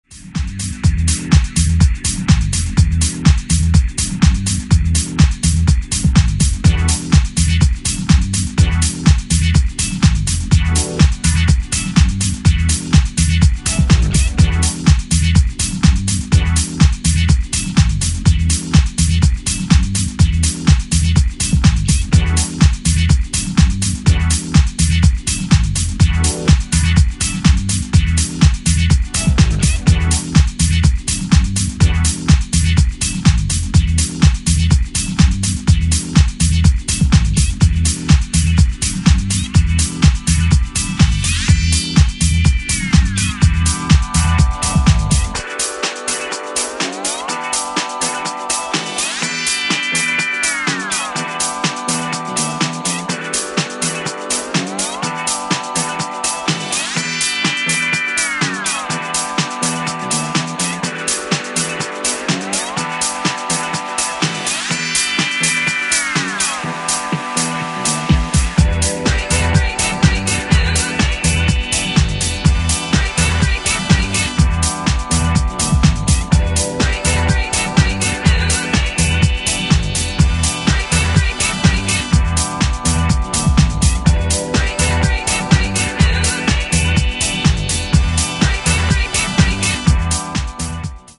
ジャンル(スタイル) DISCO HOUSE / DEEP HOUSE